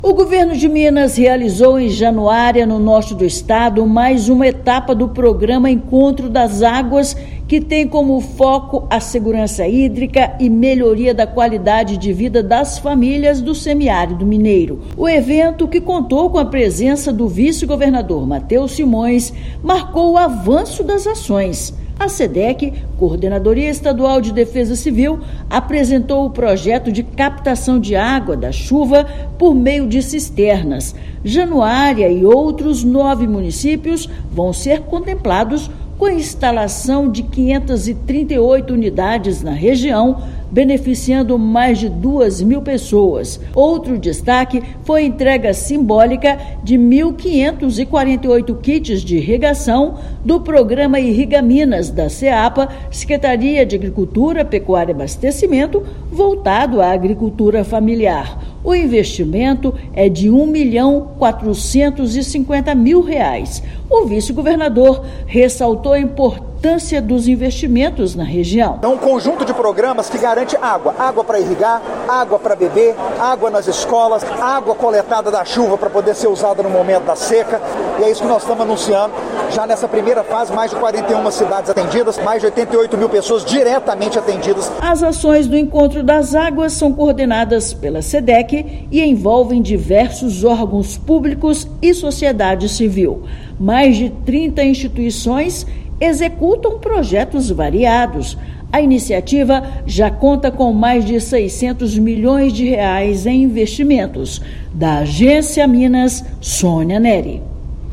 Segurança hídrica, educação, produção rural e dignidade ganham reforço nesta etapa do programa, apresentada em Januária. Ouça matéria de rádio.